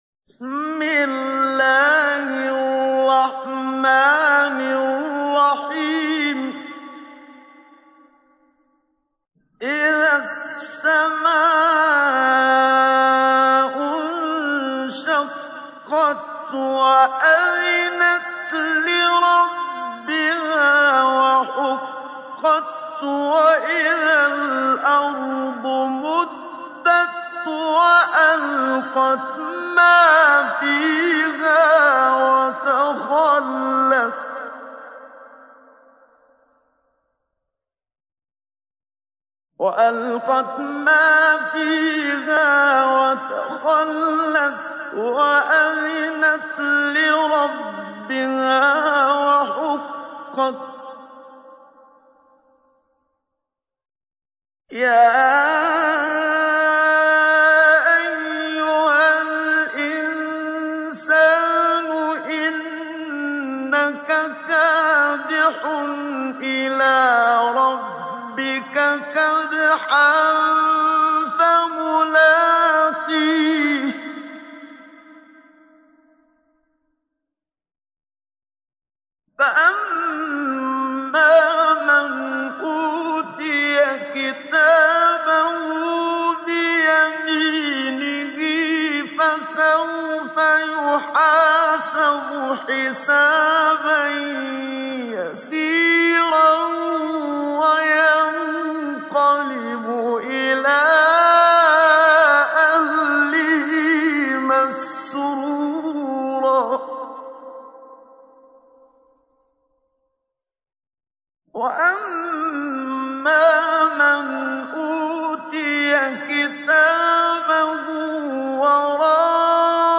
تحميل : 84. سورة الانشقاق / القارئ محمد صديق المنشاوي / القرآن الكريم / موقع يا حسين